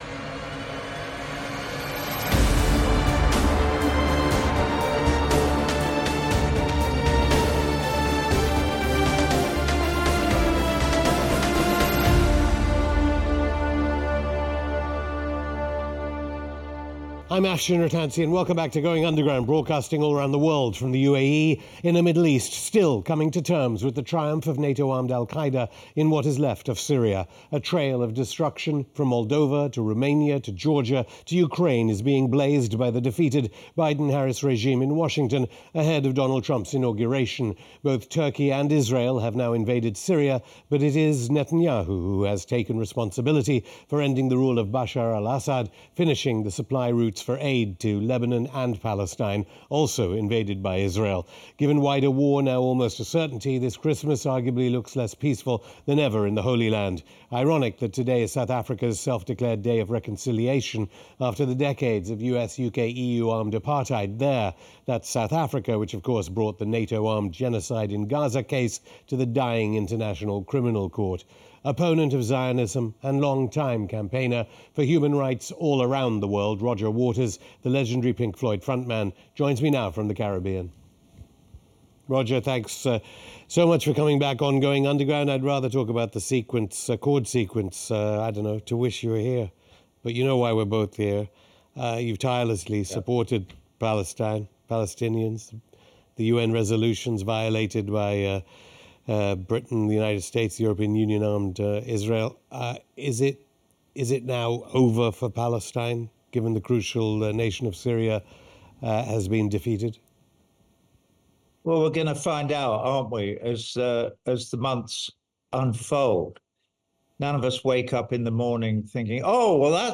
Roger Waters on the Gaza Genocide: The Mask is Off For a Dying Western Empire (Afshin Rattansi interviews Roger Waters; 16 Dec 2024) | Padverb